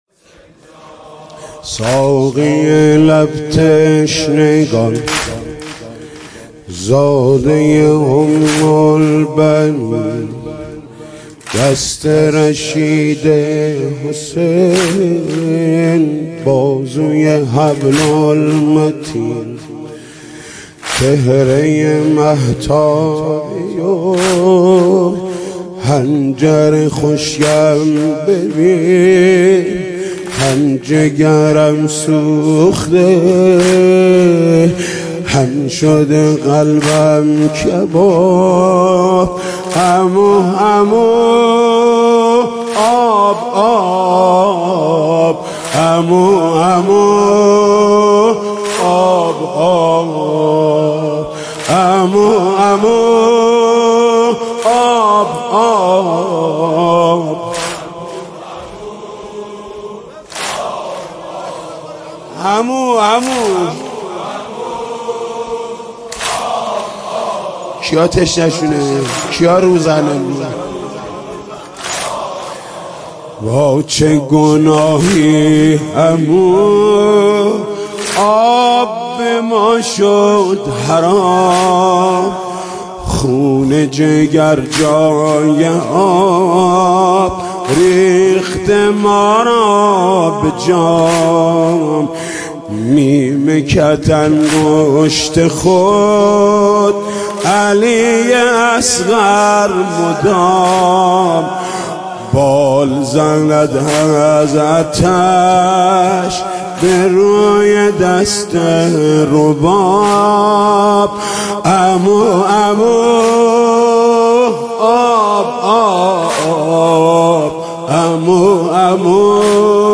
ساقى لب تشنگان زاده ى محمود کریمی شب نهم محرم 96/07/07
نوحه جديد محمود کريمی
مداحی صوتی محمود کريمی